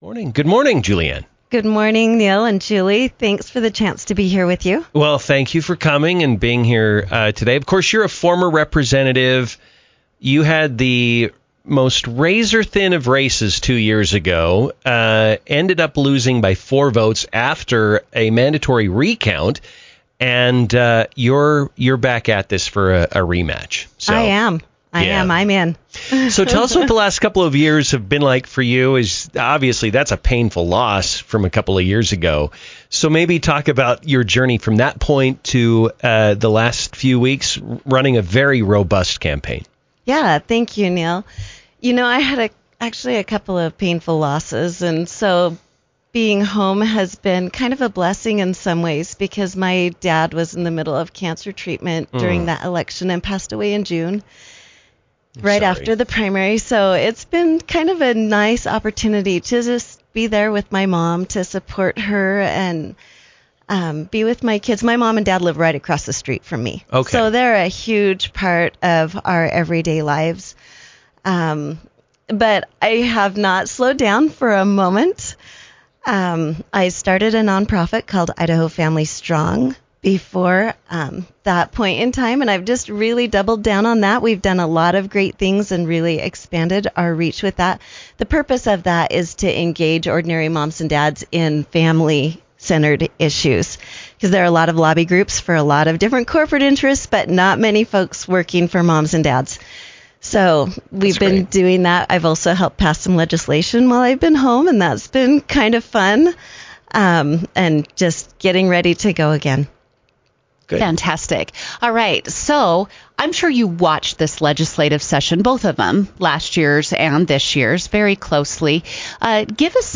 In a recent interview on Newstalk 107.9